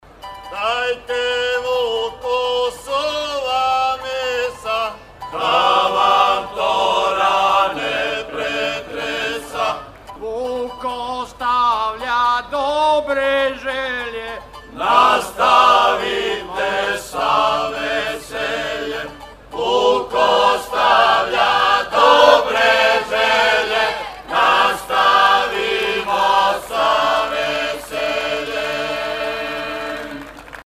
Christmas Show Songs – 2017
1st Grade